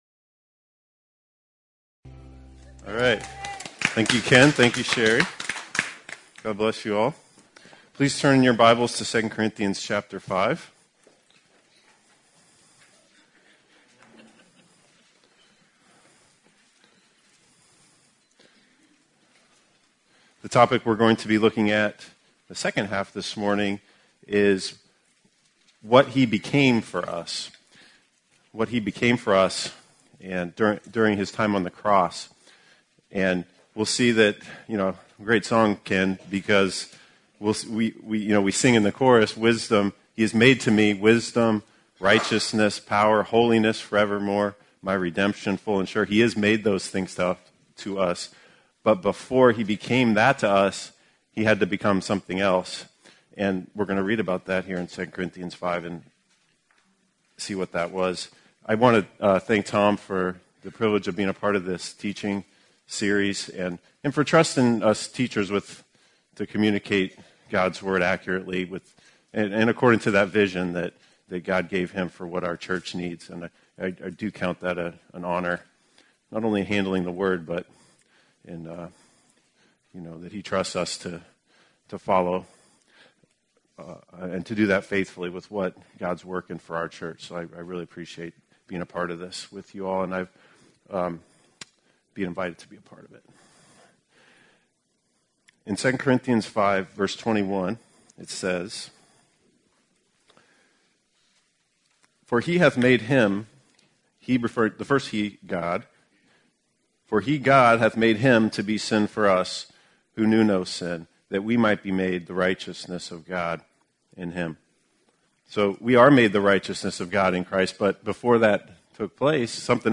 What He Became for Us Christ Jesus: The Wisdom of God (Family Camp 2017) – Part 6 August 1, 2017 Teaching 6 in a series of 14 teachings on some of the many things God has declared Jesus Christ to be to us and for us.